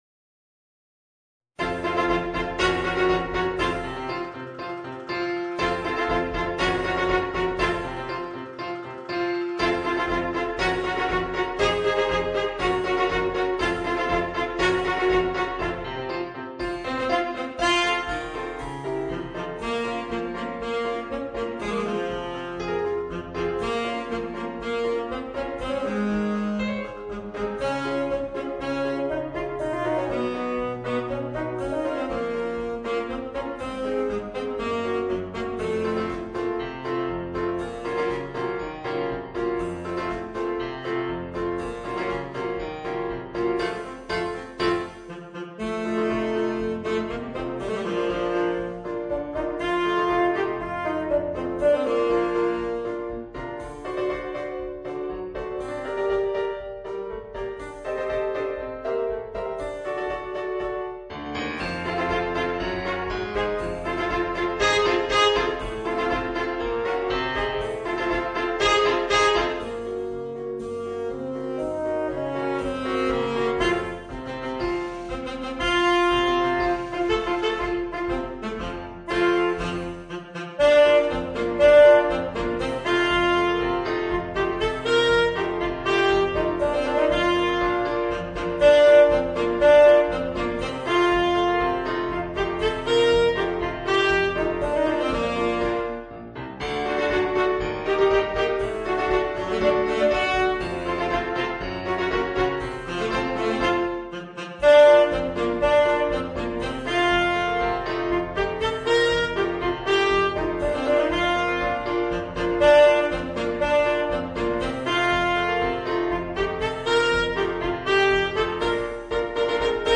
Saxophone ténor & piano (batterie & castagnettes en option)